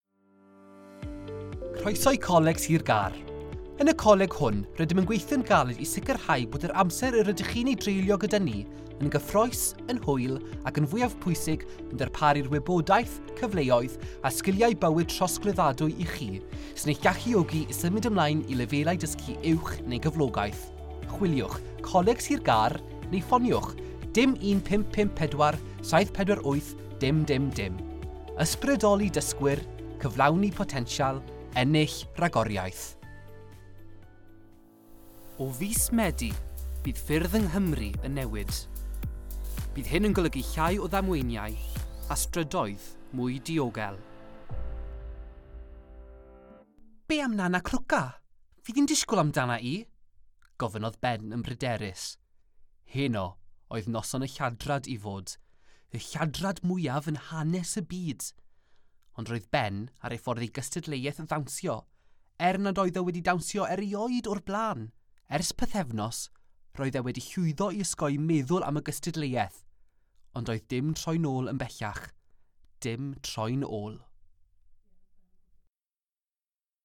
Welsh, Male, 20s-30s